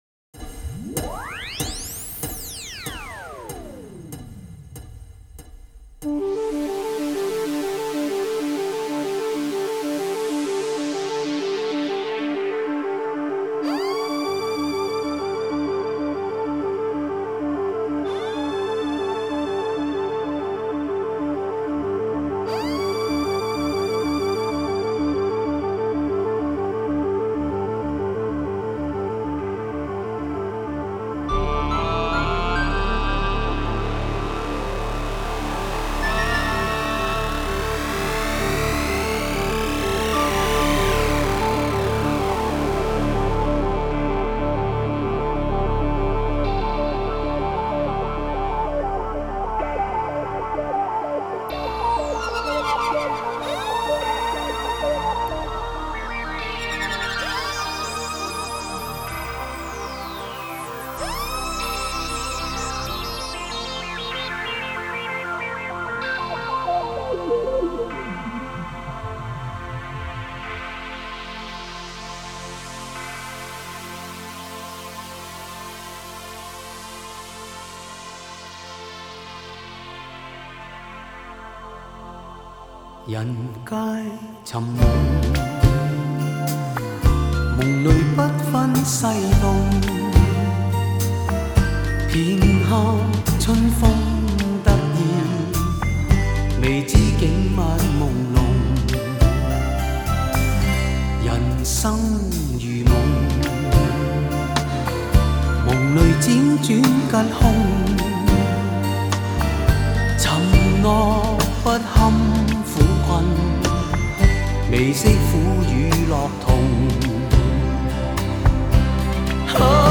国风 收藏 下载